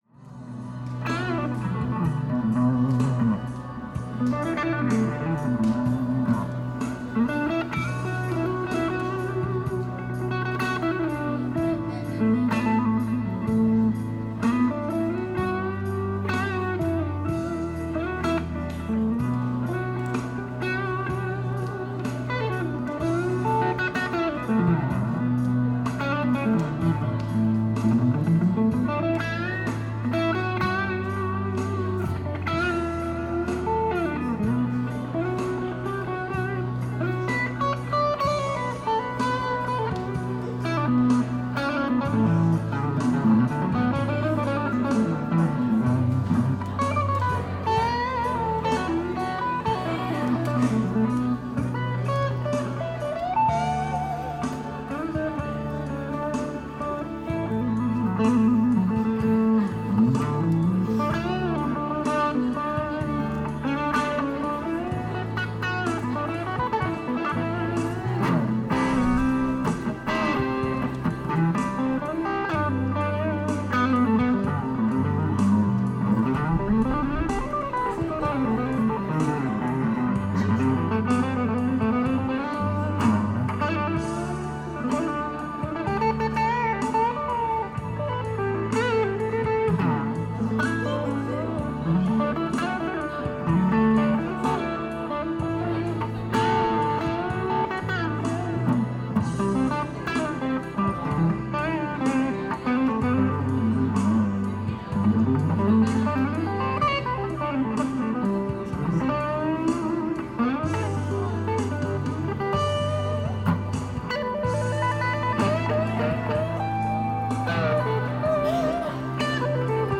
Hade mickarna och lapptoppen i väskan när jag stötte på en snubbe på blåa linjens perong på T-centralen.
OBS håll koll på nivån. Det finns en del lågfrekvent skräp som jag filtrerat till en del men jag har inte kollat noga.